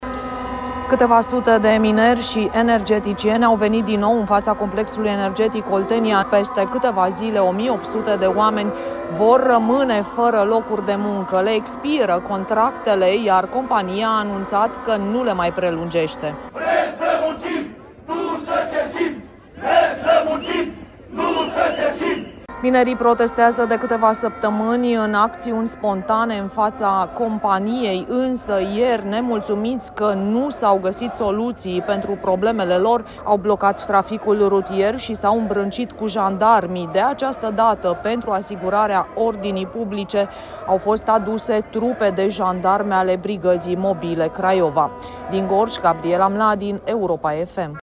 Înarmați cu vuvuzele, angajații de la CE Oltenia cer conducerii să identifice modalități pentru a-i ține pe angajați. „Vrem să muncim, nu să cerșim” , au scandat protestatarii în fața sediului companiei.